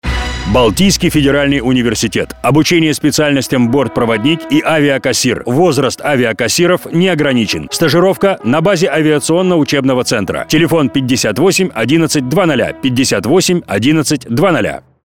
Информационный ролик